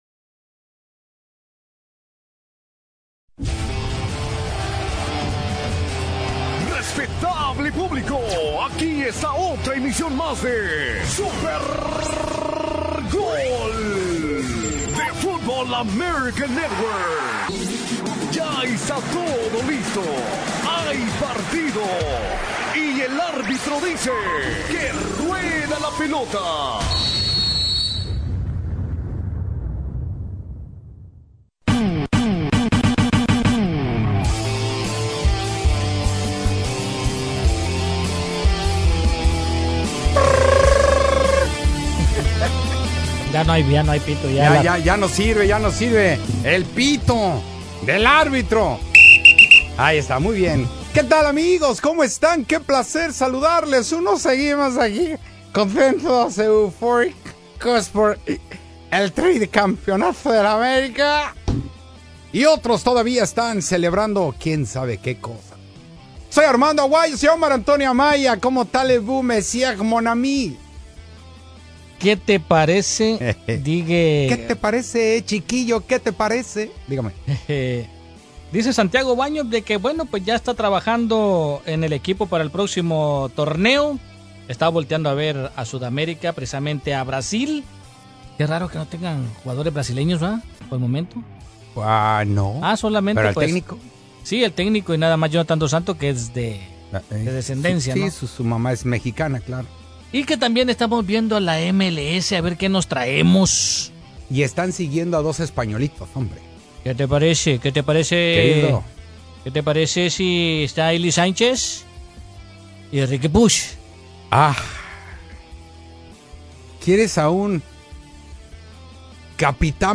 AMERICA DA UNA LISTA DE SUSJUGADORES QUE PODRIAS DEJAR EL NIDO. ESCUCHAMOS EL DISCURSO DE MOTIVACION DE HENRY MARTIN. PACHUCA ESTARA ENFRENTANDO AL REAL MADRID DENTRO DEL TORNEO INTERCONTINENTAL.